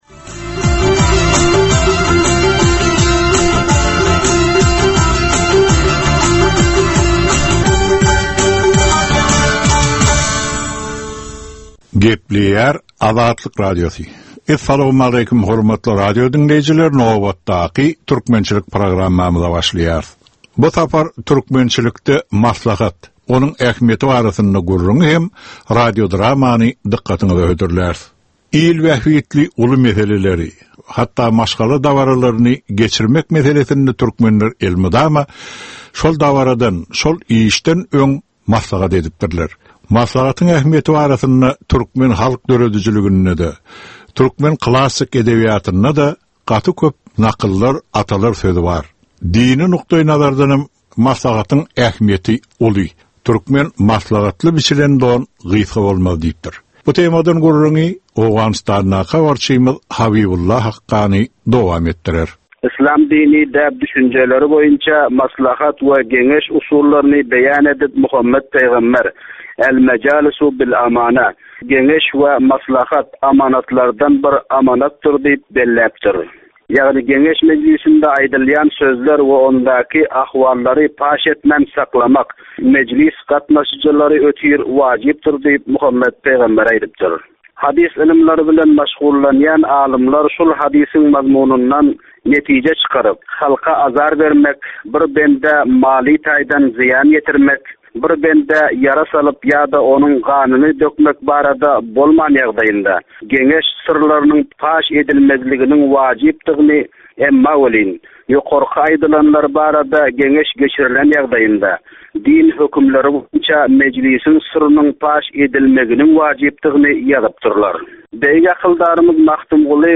Türkmen halkynyň däp-dessurlary we olaryň dürli meseleleri barada ýörite gepleşik. Bu programmanyň dowamynda türkmen jemgyýetiniň şu günki meseleleri barada taýýarlanylan radio-dramalar hem efire berilýär.